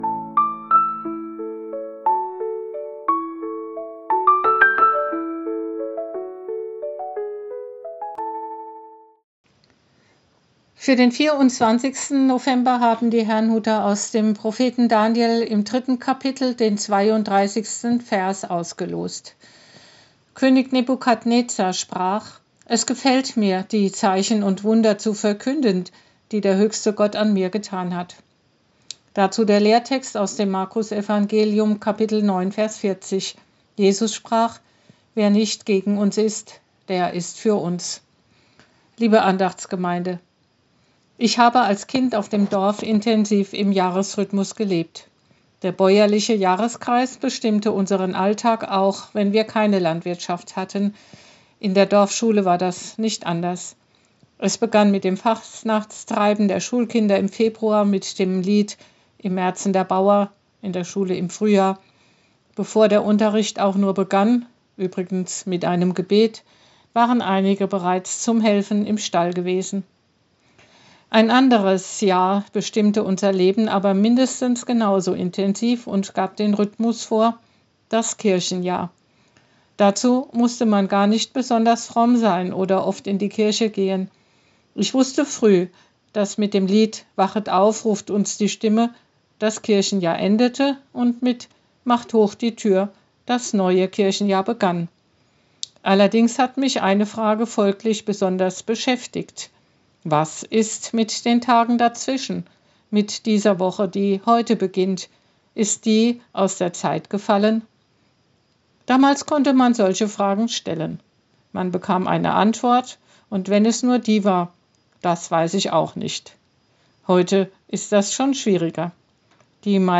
Losungsandacht für Montag, 24.11.2025 – Prot.